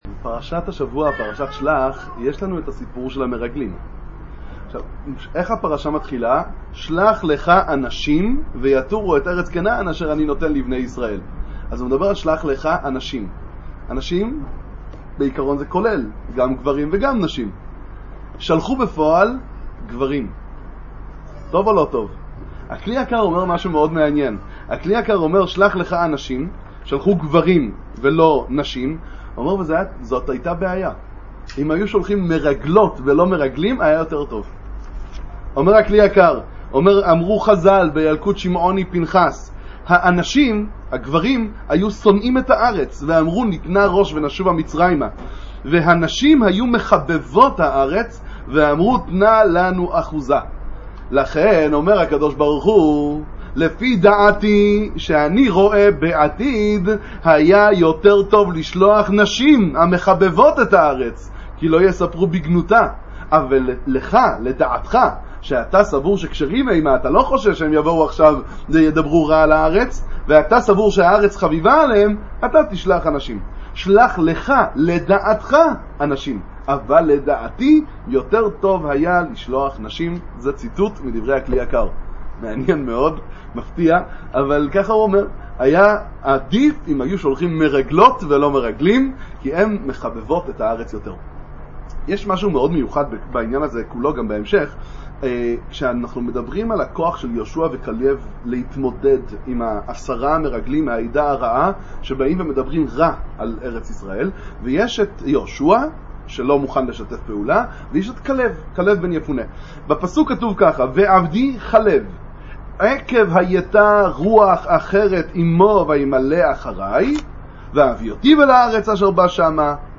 התנצלות: הוידיאו באיכות פחות טובה מהרגיל עקב בעיות במצלמה, מקוים לחזור בקרוב לאיכות הרגילה.